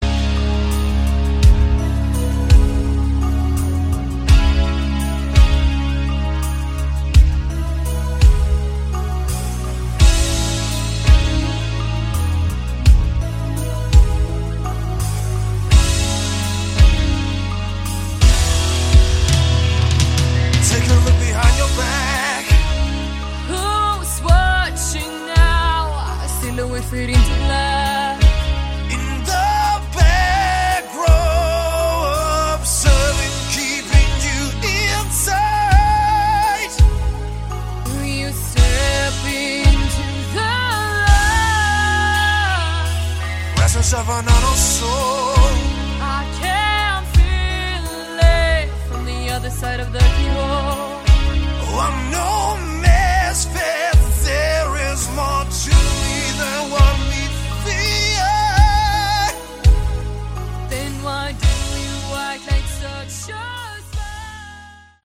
Category: Melodic Rock
keyboards, vocals
guitar, keyboards
bass
drums